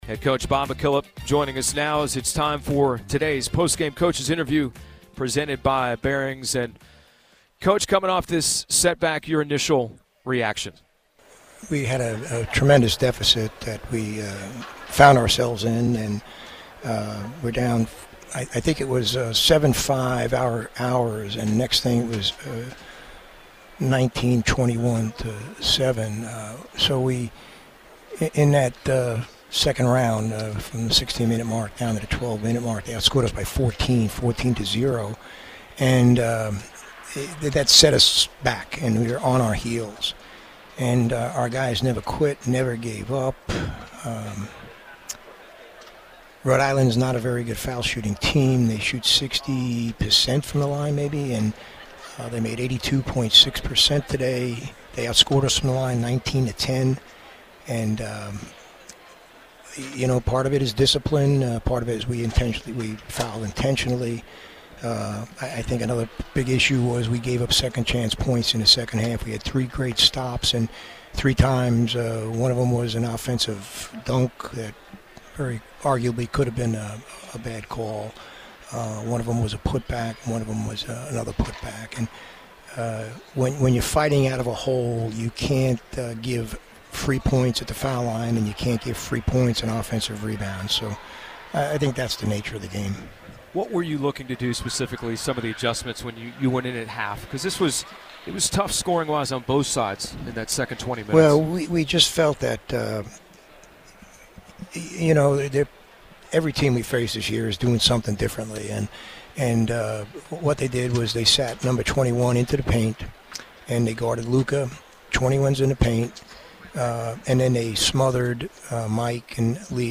McKillop Postgame Radio Interview